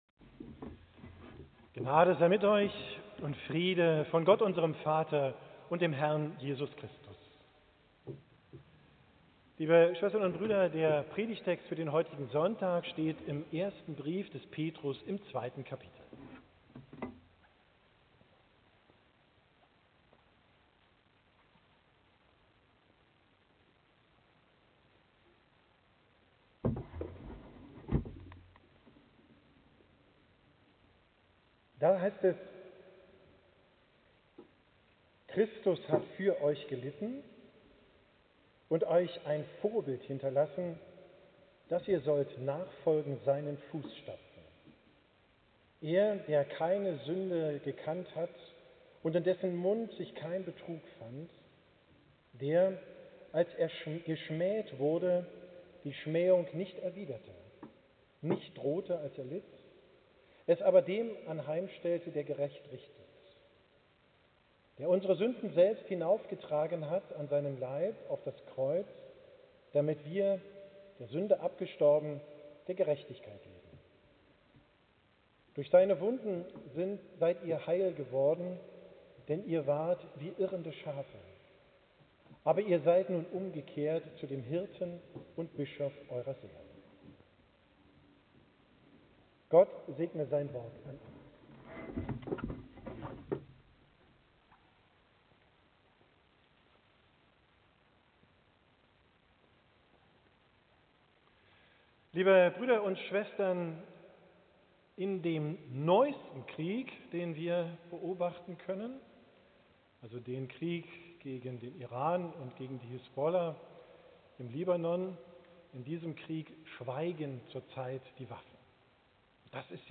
Predigt vom Sonntag Misericordias Domini, 19.